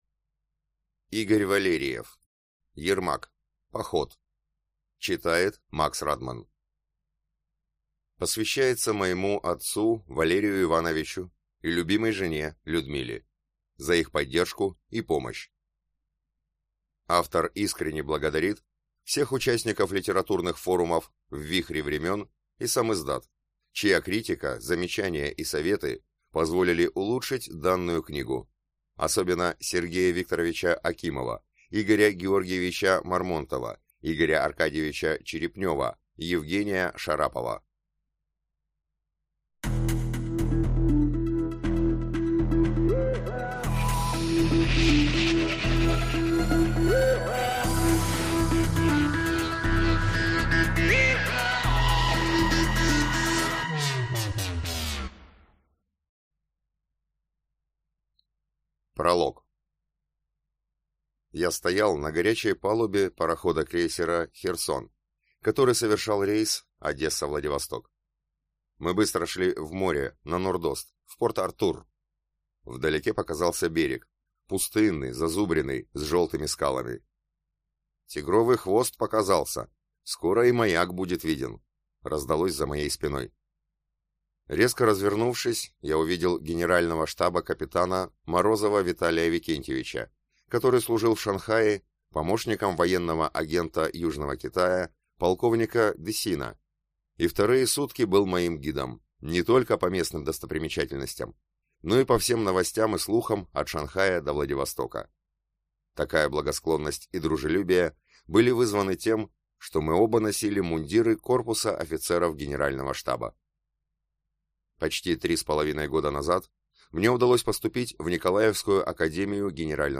Аудиокнига Ермак. Поход | Библиотека аудиокниг